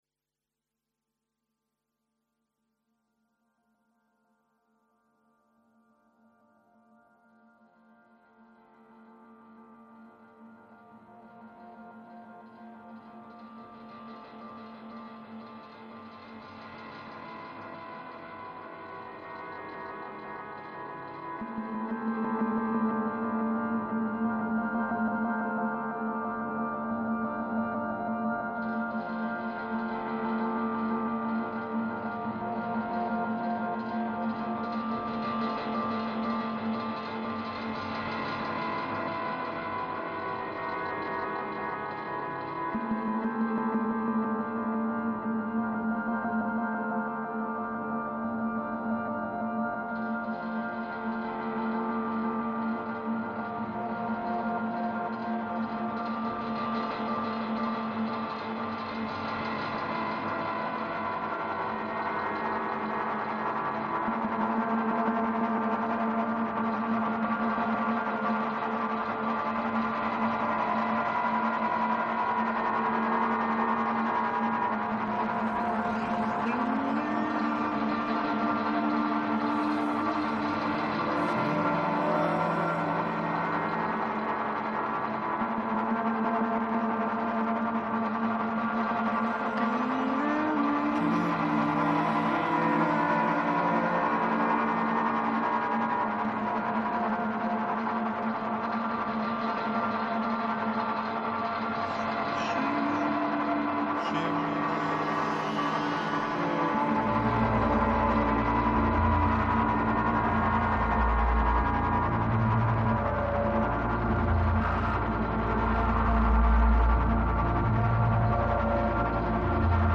soundinstallation